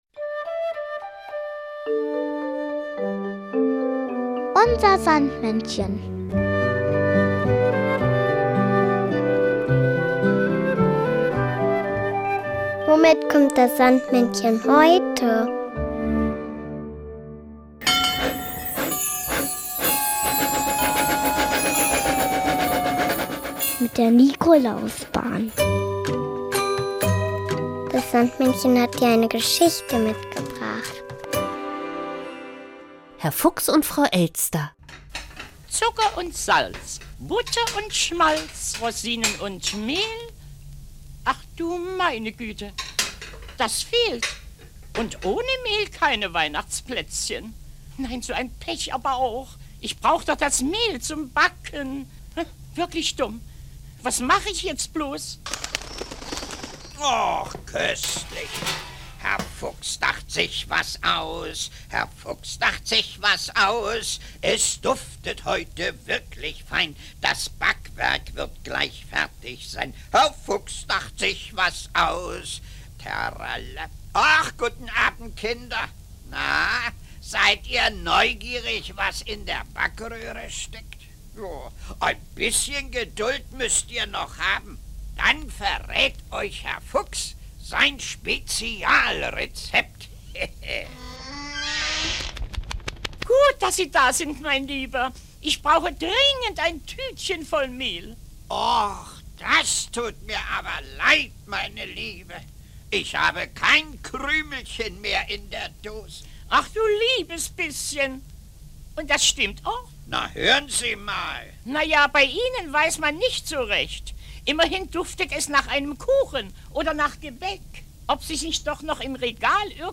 Weihnachtslied "Bratapfel" von Veronika Fischer.